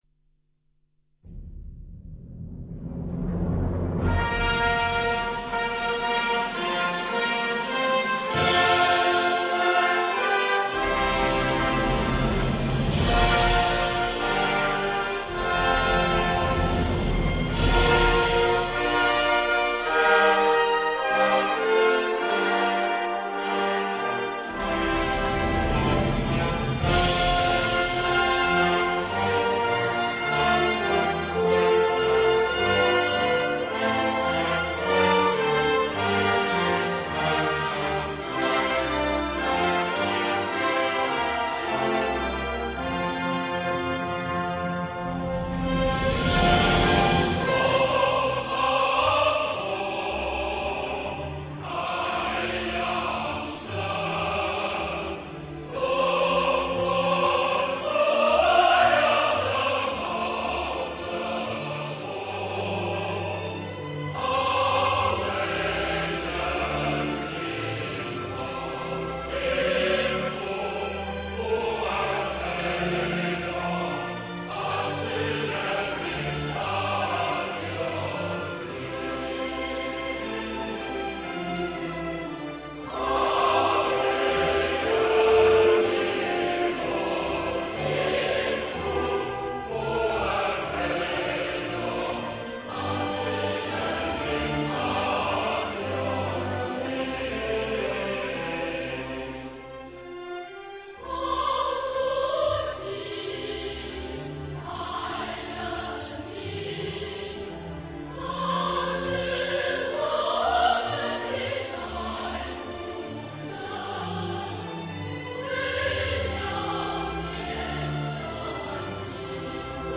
歌唱版RA